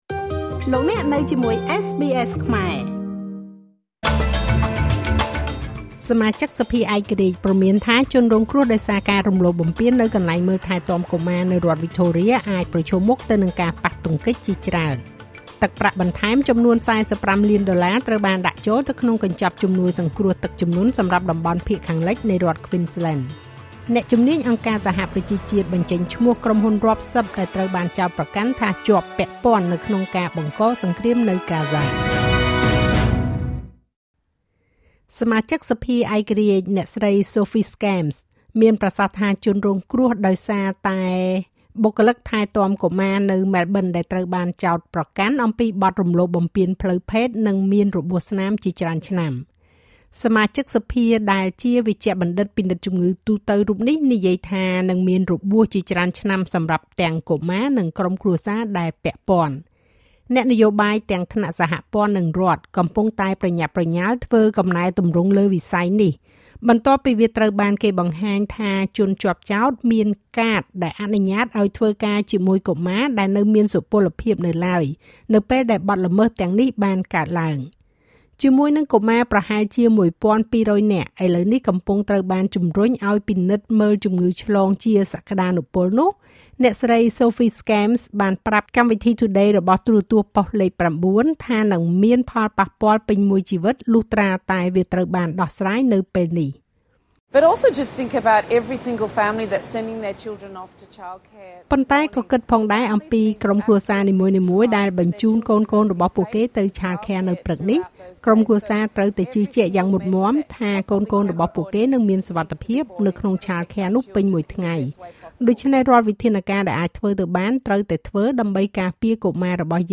នាទីព័ត៌មានរបស់SBSខ្មែរ សម្រាប់ថ្ងៃសុក្រ ទី៤ ខែកក្កដា ឆ្នាំ២០២៥